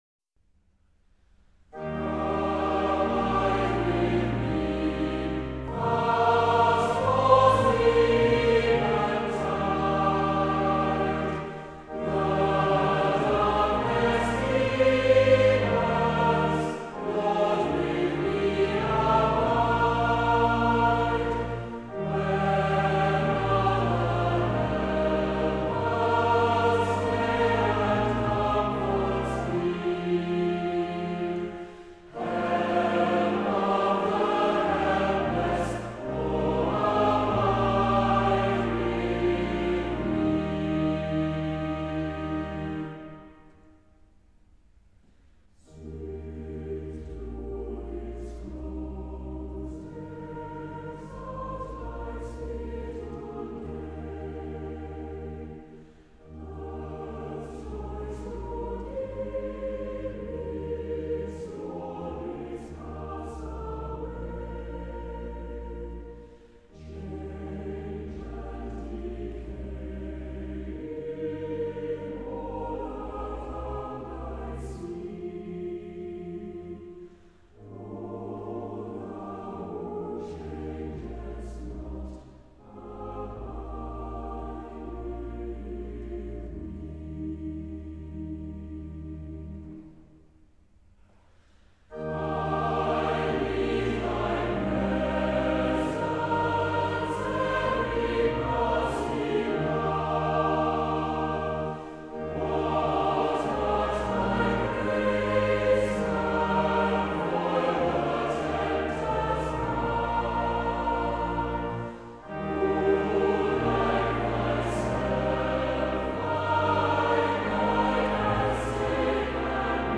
A timeless evening hymn of comfort and trust